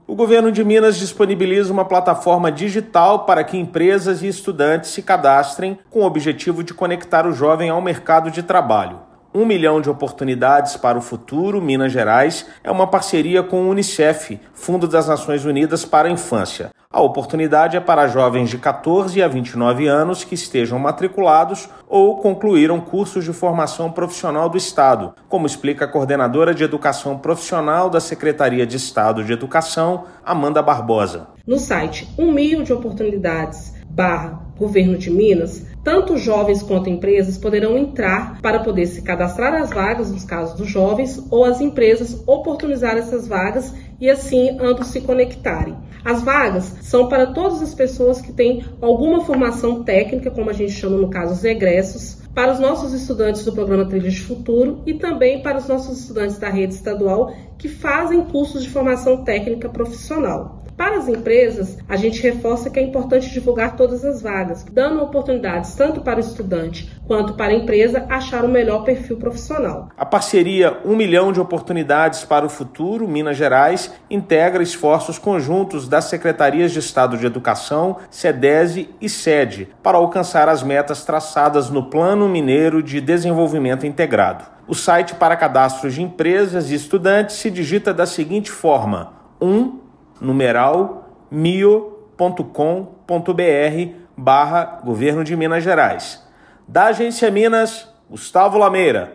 Em parceria com Unicef, Governo lança “1mio-Minas Gerais” para unir oportunidades de emprego a jovens com cursos profissionalizantes. Ouça matéria de rádio.